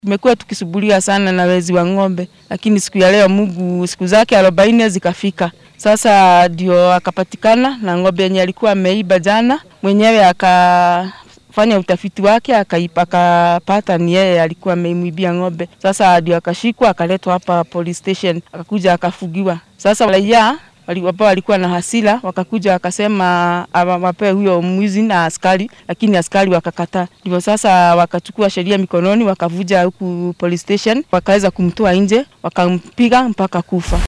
Haweeney ka mid ah dadweynaha deegaanka Kinangop ayaa ka warramaysa sida ay wax u dhaceen.